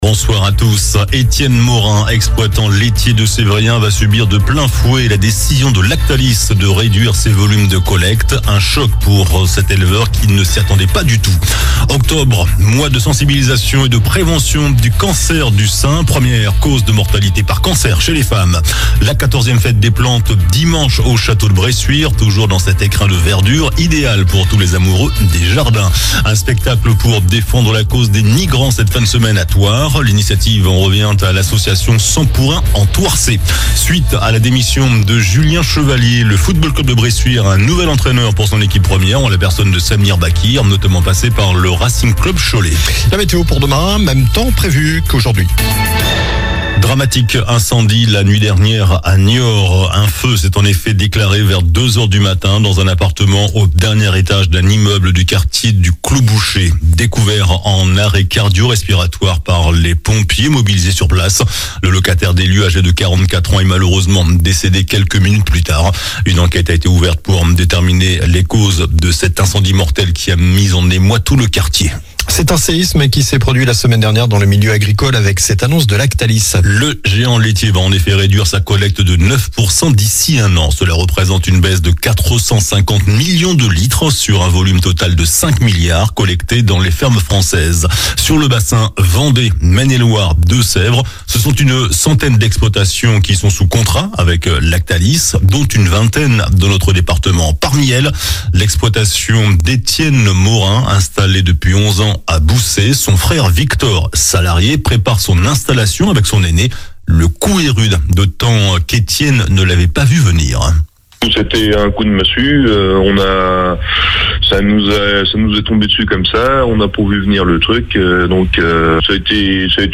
Journal du mercredi 2 octobre (soir)